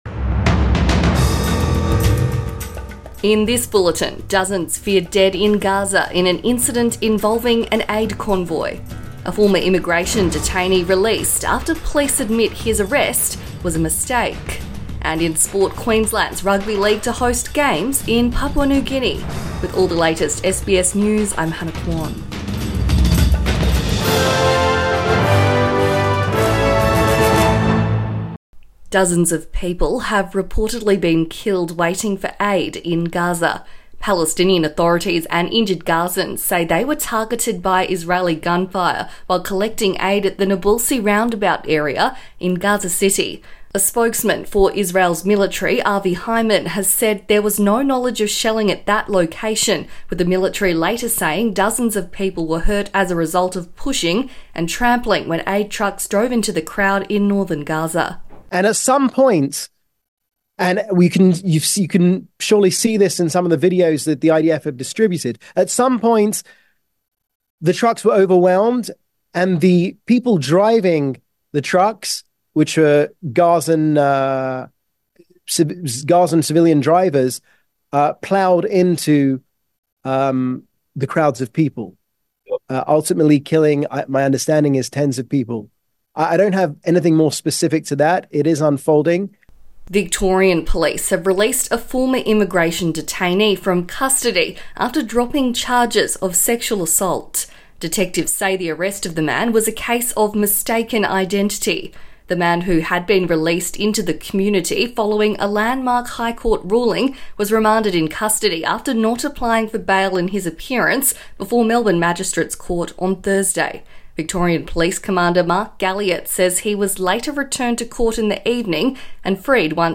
Morning News Bulletin 1 March 2024